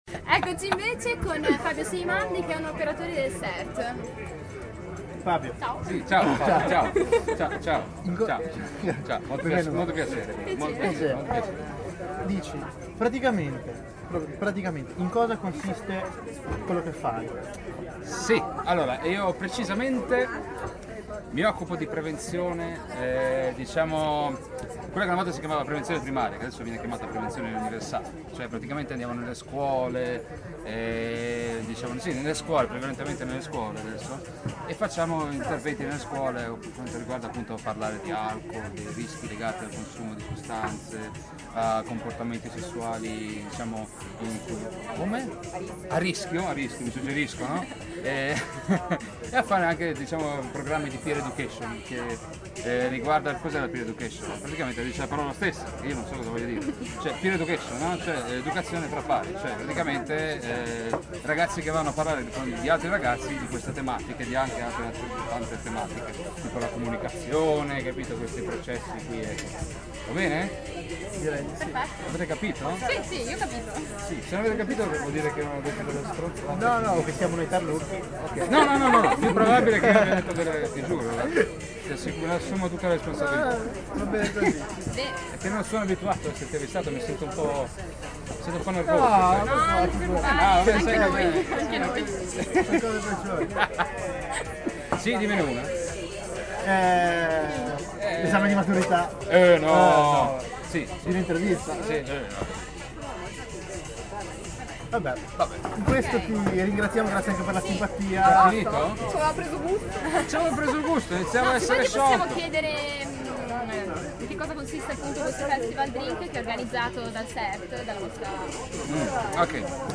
Intervista a operatore SERT in occasione del Festival Drink